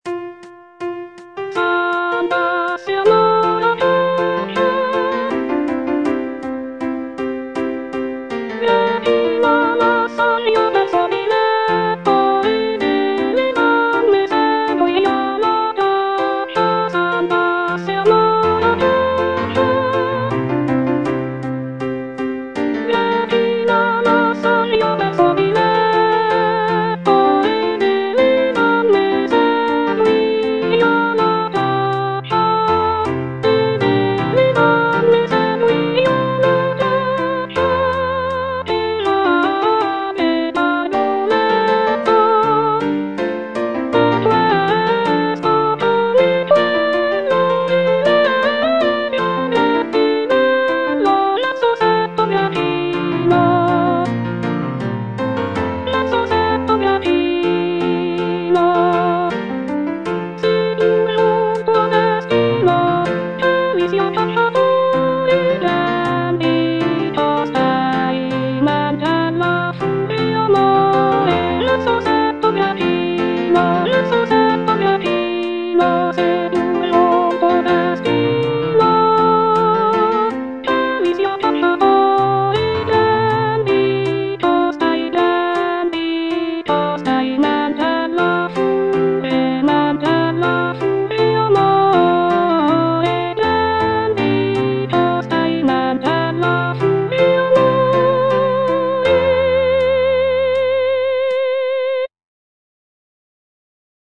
C. MONTEVERDI - S'ANDASSE AMOR A CACCIA Soprano II (Voice with metronome, piano) Ads stop:
"S'andasse Amor a caccia" is a madrigal composed by Claudio Monteverdi, an Italian composer from the late Renaissance period.